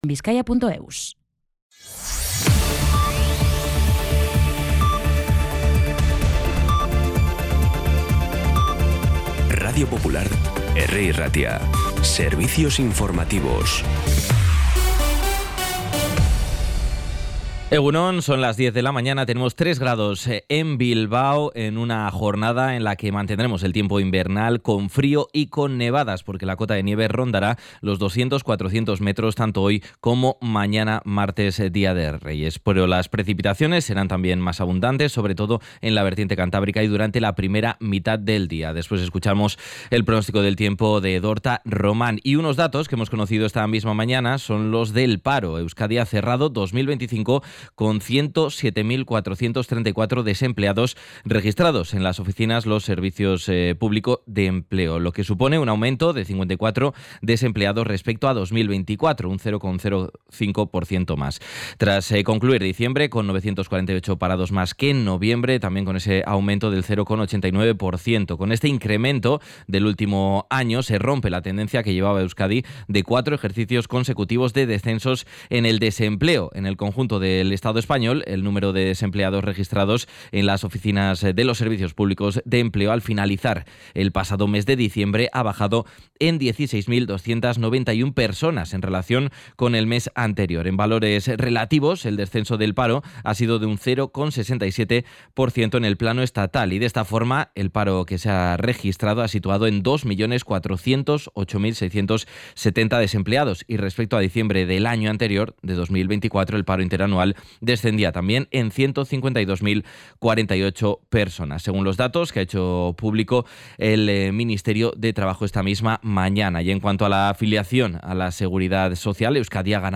Los titulares actualizados con las voces del día.